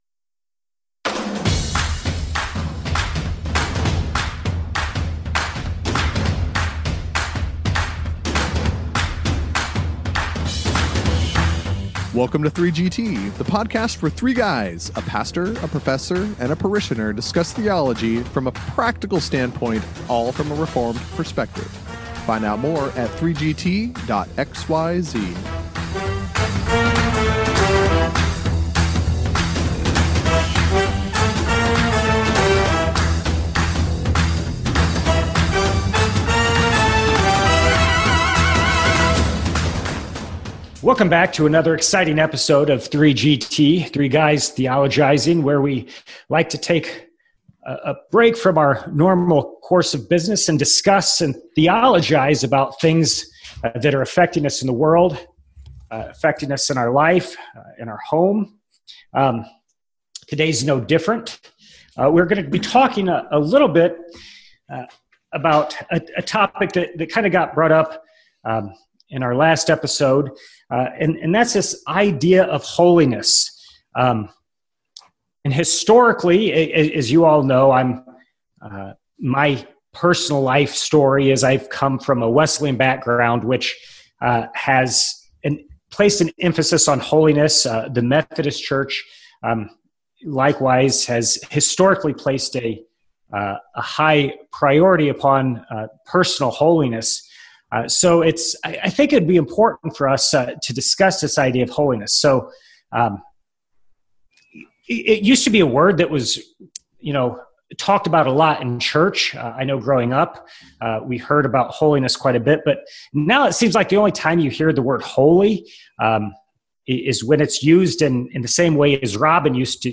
The guys meditate and discuss several questions that arise from this warning.